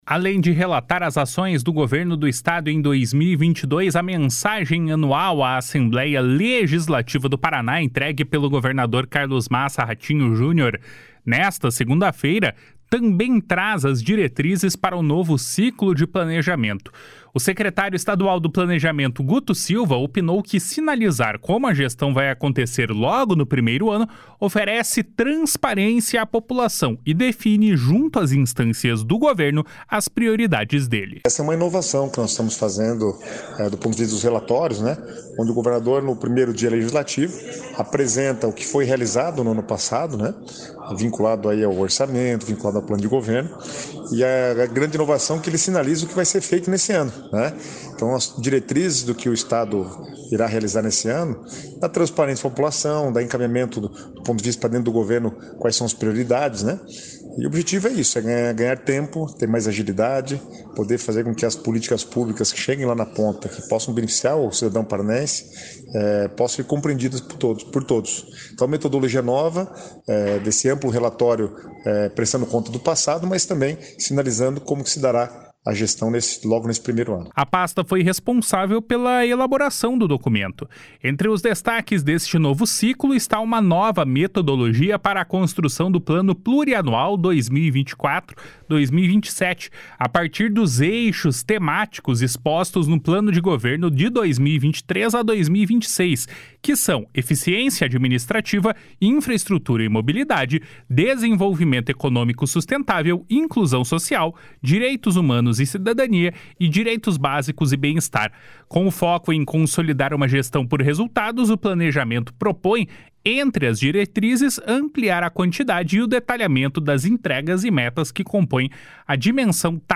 Além de relatar as ações do Governo do Estado em 2022, a mensagem anual à Assembleia Legislativa do Paraná, entregue pelo governador Carlos Massa Ratinho Junior nesta segunda-feira, também traz as diretrizes para o Novo Ciclo de Planejamento. O secretário estadual do Planejamento, Guto Silva, opinou que sinalizar como a gestão vai acontecer logo no primeiro ano oferece transparência à população e define, junto às instâncias do governo, as prioridades dele.